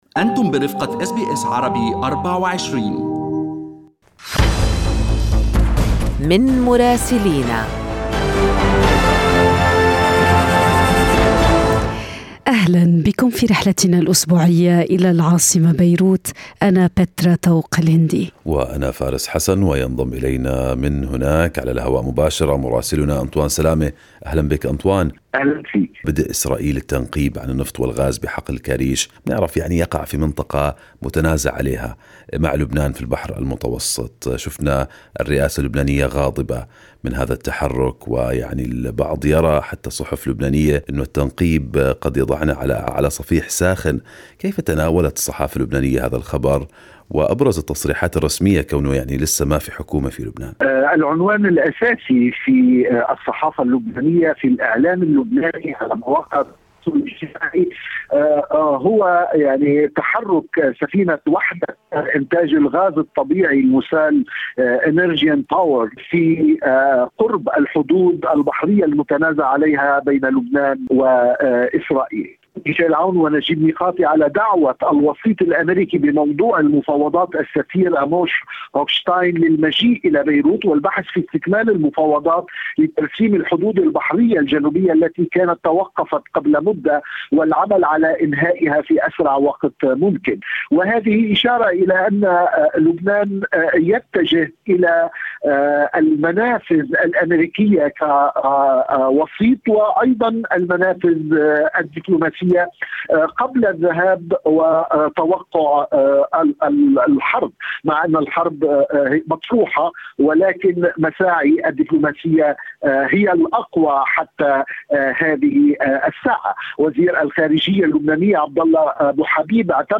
من مراسلينا: أخبار لبنان في أسبوع 7/6/2022